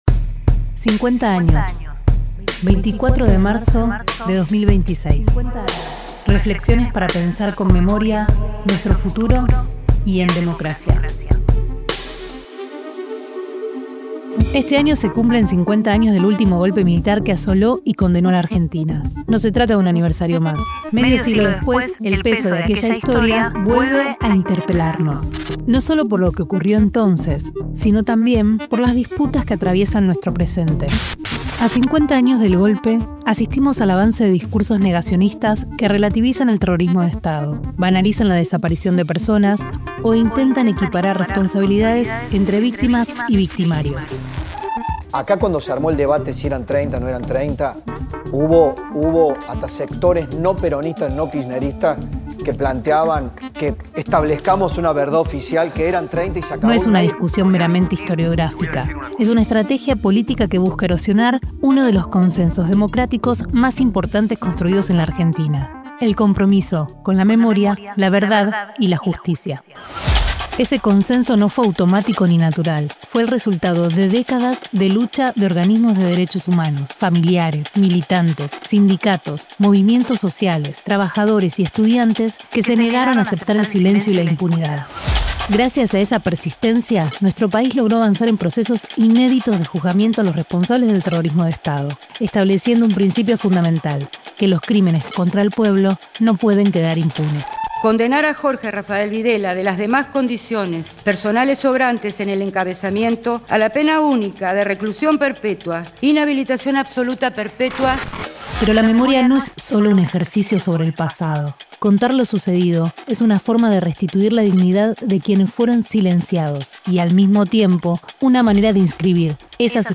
Música: “Recordé” – Milo J. Material de archivo disponible en internet.